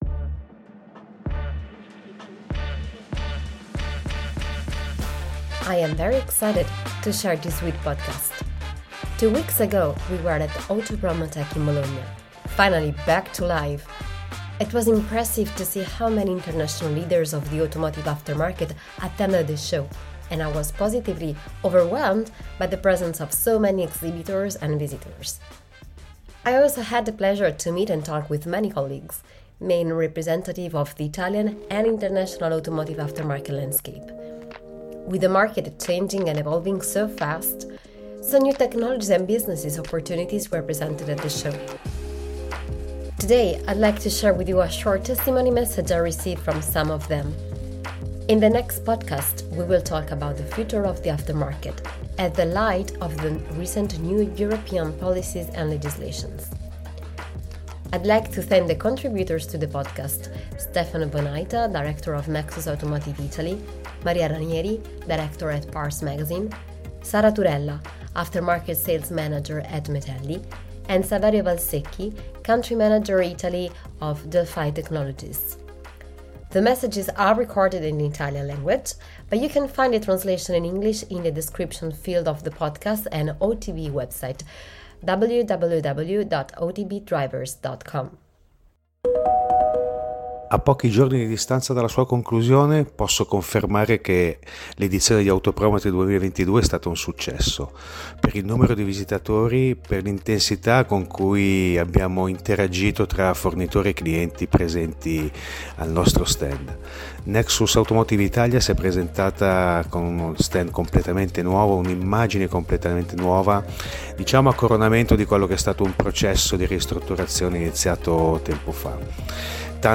Tags: Automotive aftermarket, English Language, Interviews, Italy, Manufacturers, Trade show
Here, I’d like to share with you a short testimony message I received from some of them.